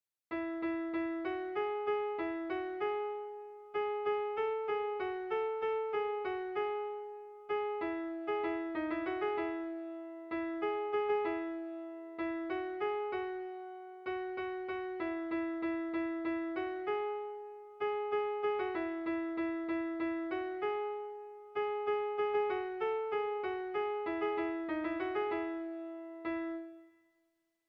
Kontakizunezkoa
Zortzikoa, berdinaren moldekoa, 7 puntuz (hg) / Zazpi puntukoa, berdinaren moldekoa (ip)
ABD..